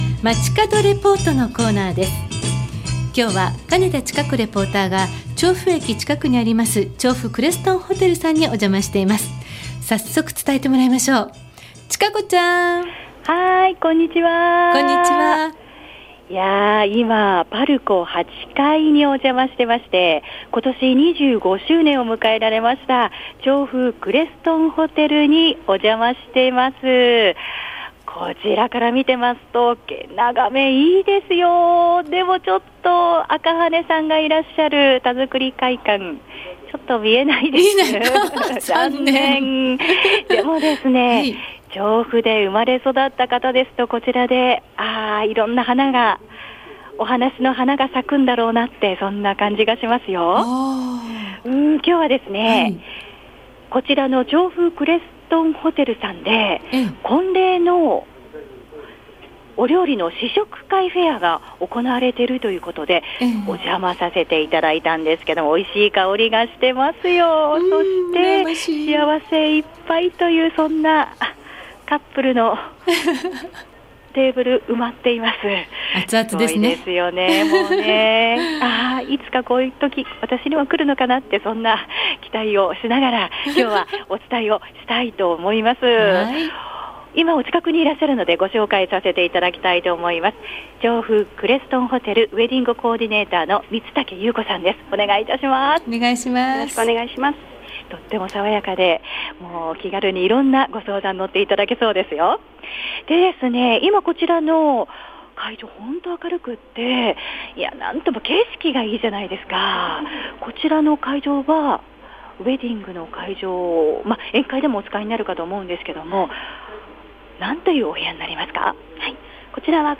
月曜日の街角レポート